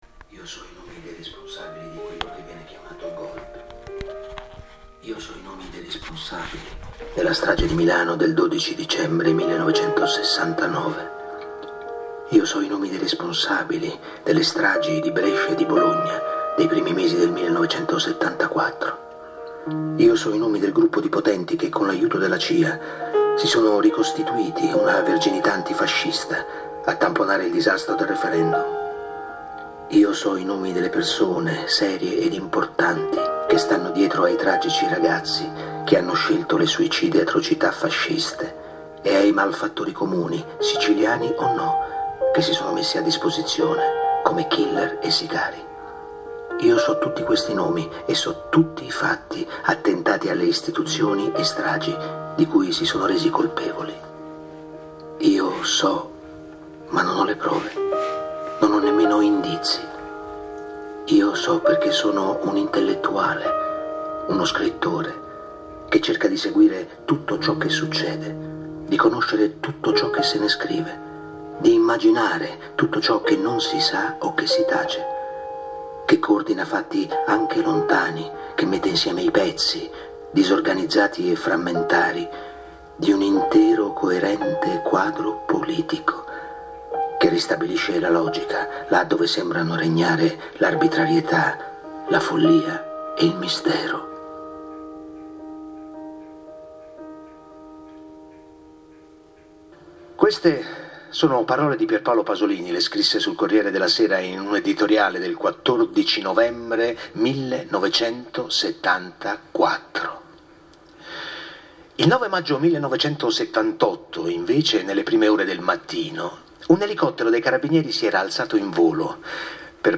55 GIORNI, L’ITALIA SENZA MORO, orazione civile di LUCA ZINGARETTI, Rai 1, 9 maggio 2018